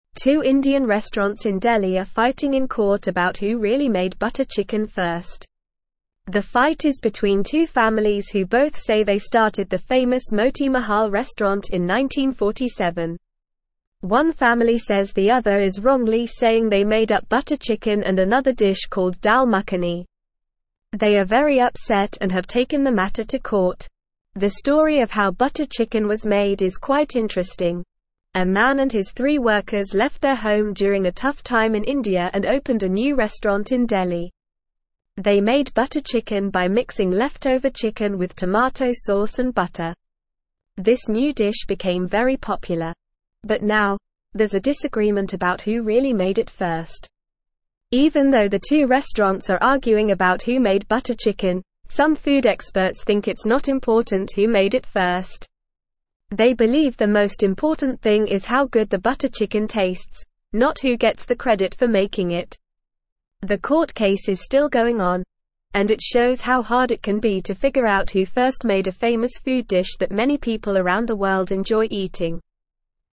Normal
English-Newsroom-Lower-Intermediate-NORMAL-Reading-Butter-Chicken-Battle-Who-Made-It-First.mp3